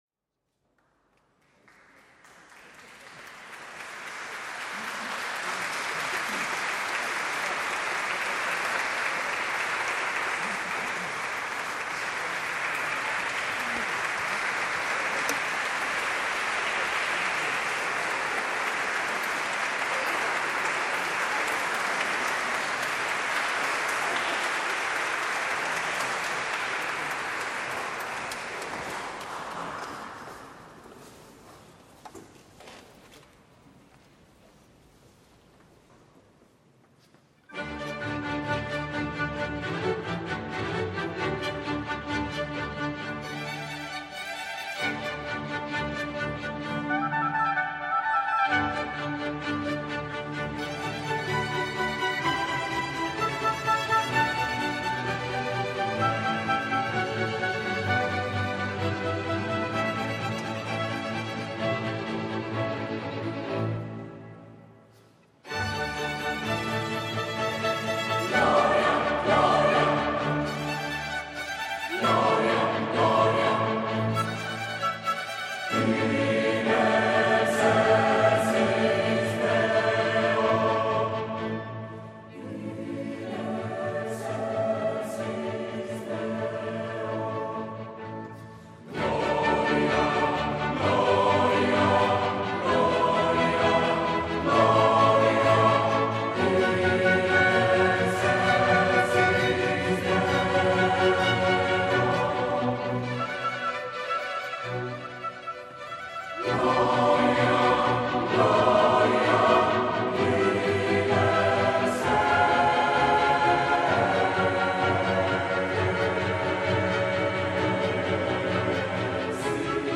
Εθνική Συμφωνική Ορχήστρα της ΕΡΤ και Χορωδία Ε.Μ.Π.
Ιερός Καθολικός Καθεδρικός Ναός